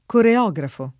[ kore 0g rafo ]